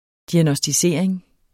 Udtale [ diagnʌsdiˈseɐ̯ˀeŋ ]